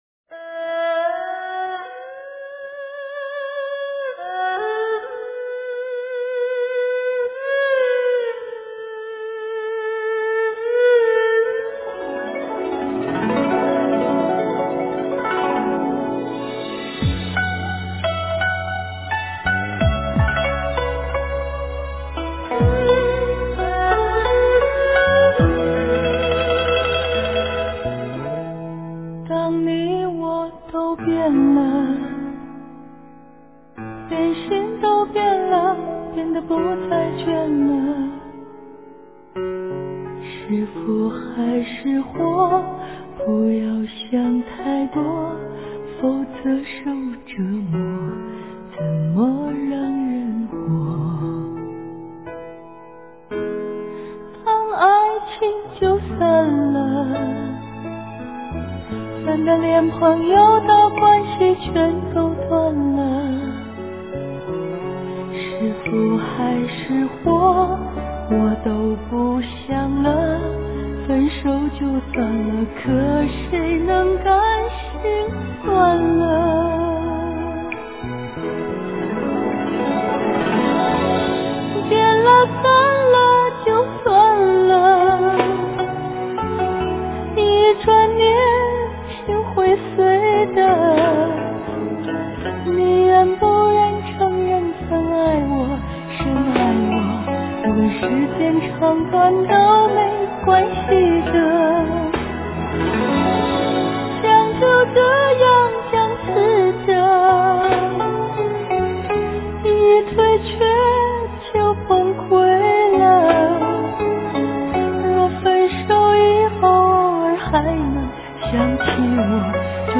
标签: 佛音凡歌佛教音乐